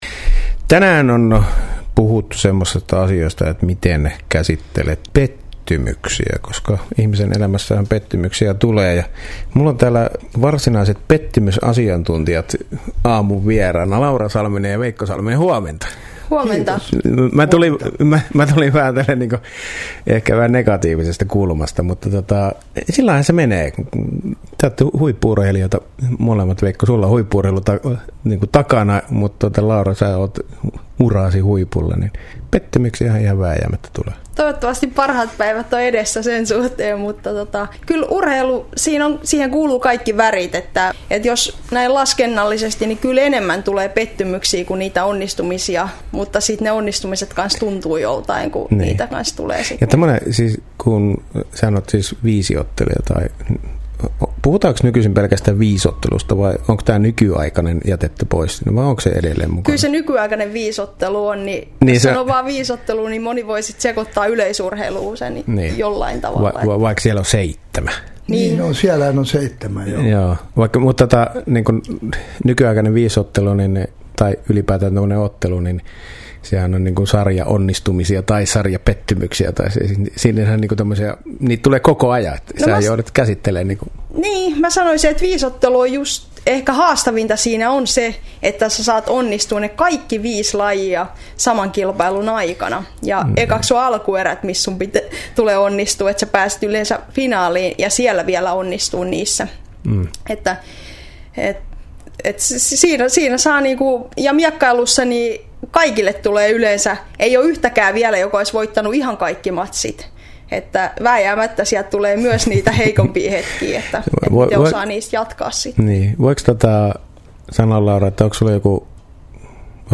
haastattelu Radio Dein Herätys! -aamulähetyksessä